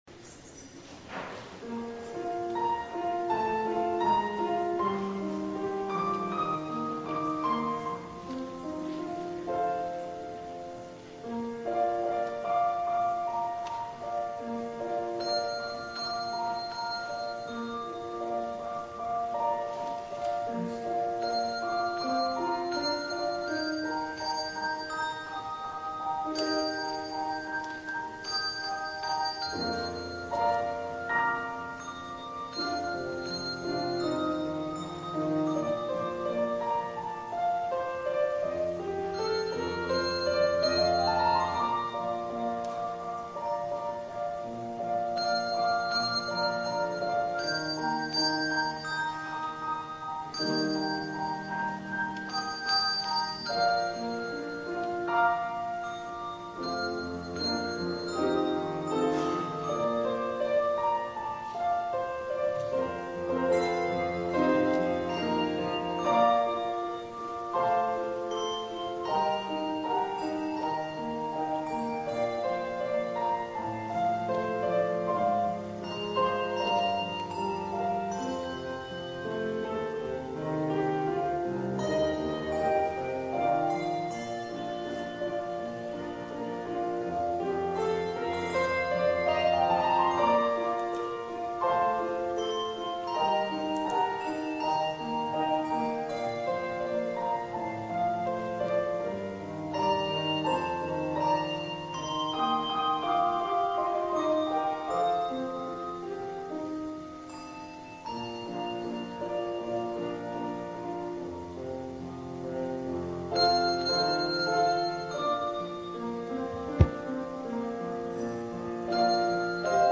for solo handbells